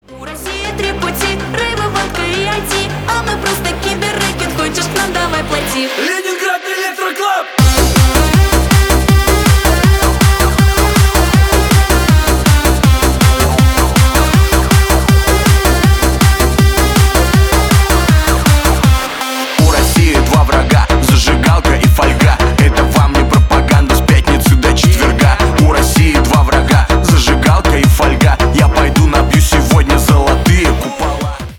Электроника
громкие # ритмичные